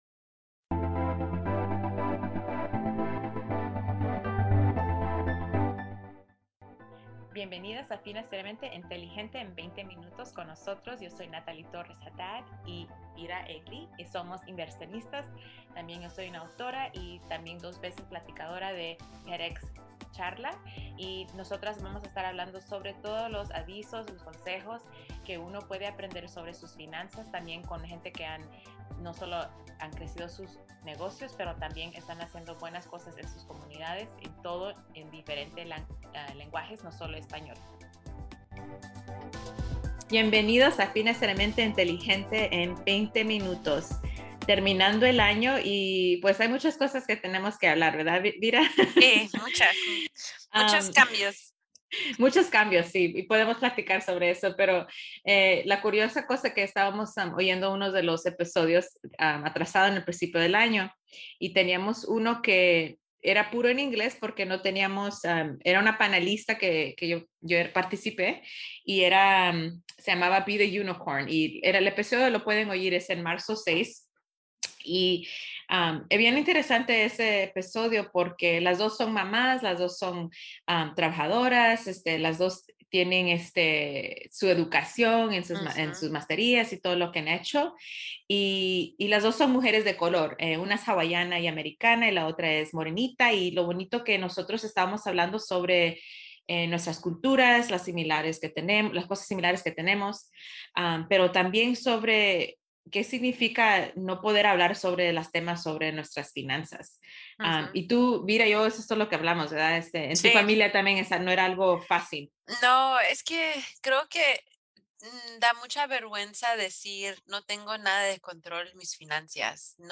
Escuchen entrevistas íntimas con unas amigas y panelistas de la conferencia CSULB Cal State University Long Beach Rise and Resist.